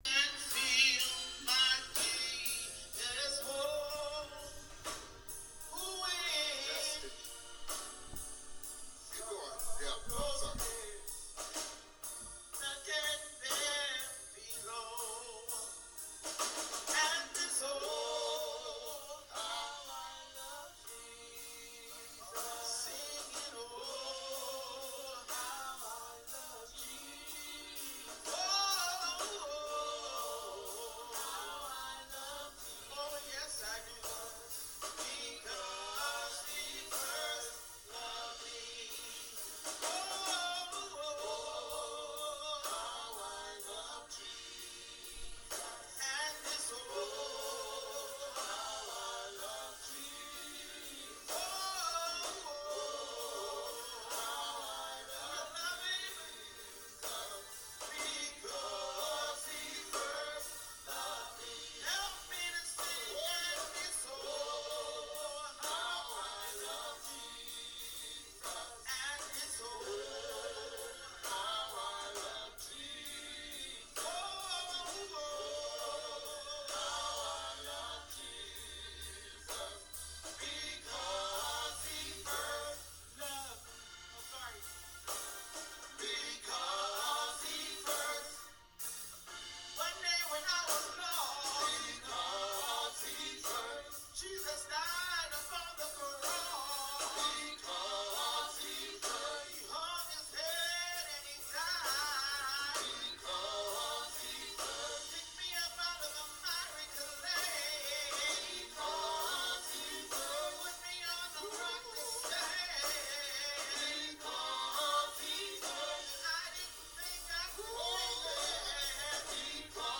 Devotion Oh How I Love Jesus (Congregational)